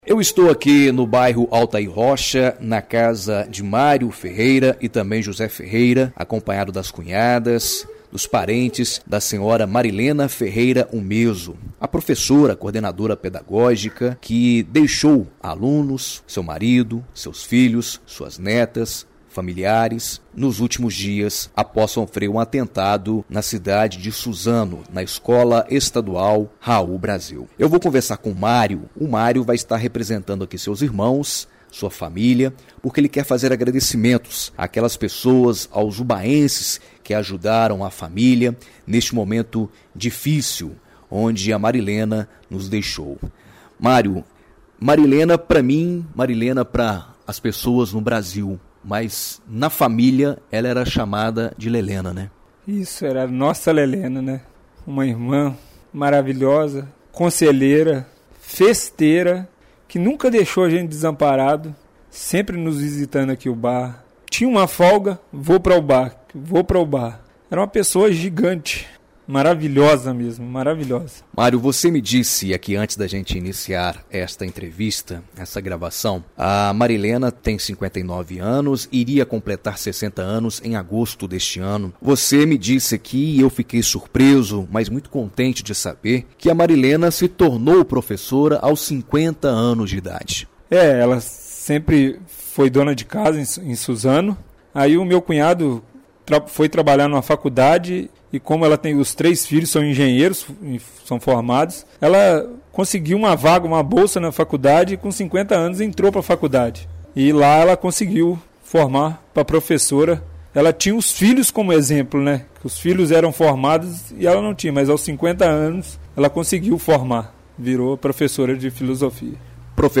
ENTREVISTA EXIBIDA NA RÁDIO EDUCADORA AM/FM UBÁ – MG